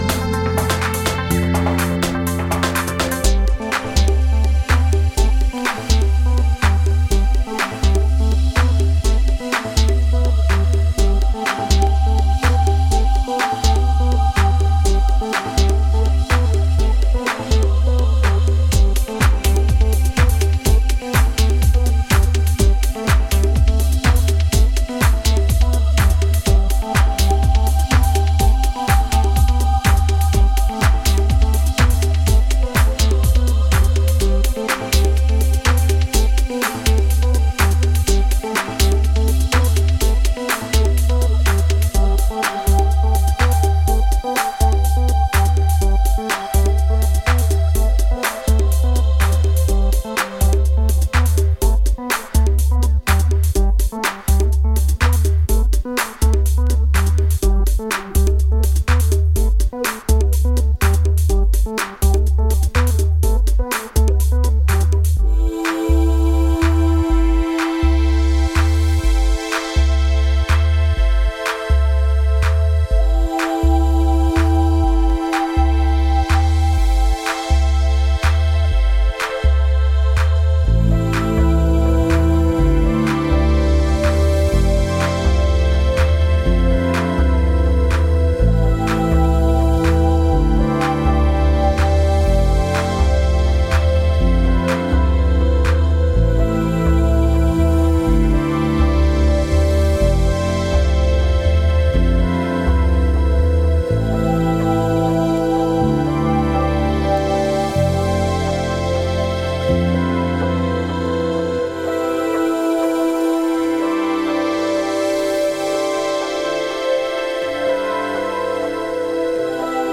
エレクトロニカ/テクノ・ユニット